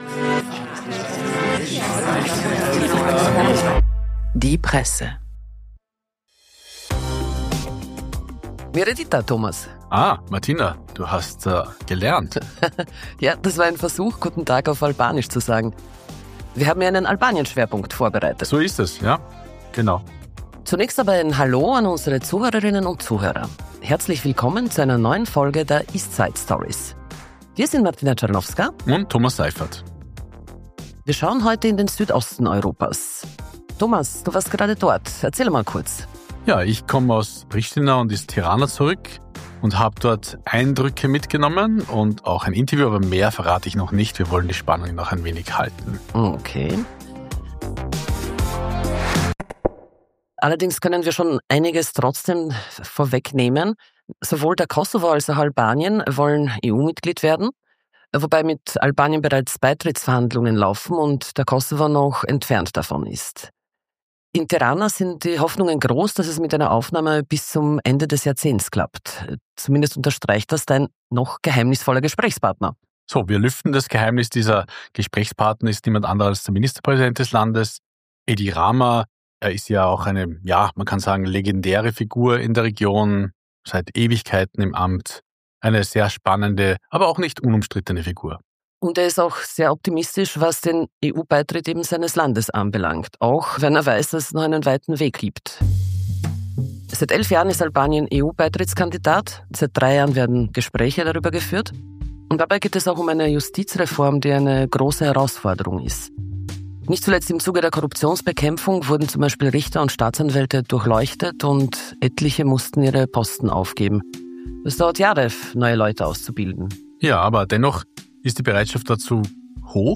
Der albanische Premierminister Edi Rama im Gespräch über Albaniens Chancen auf einen EU-Beitritt, den Kampf gegen Korruption und das ambivalente Verhältnis von Albanien zu Russland.